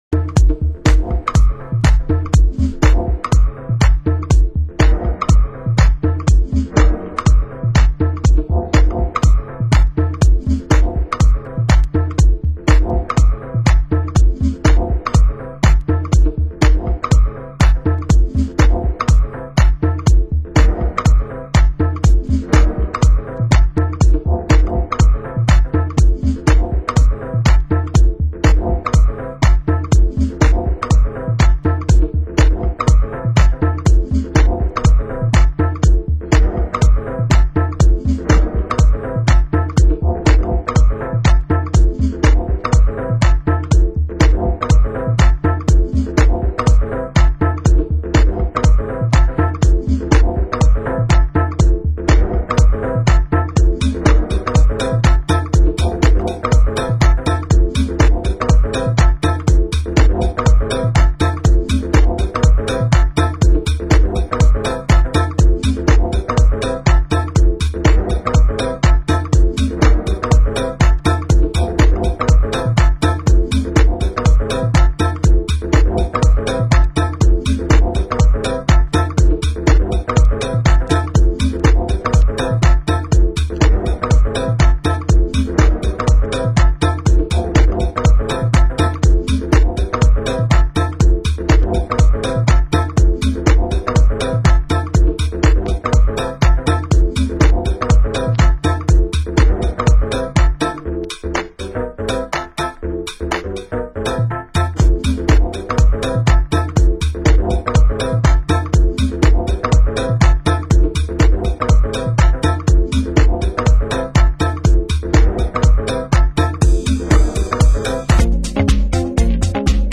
Genre: Tech House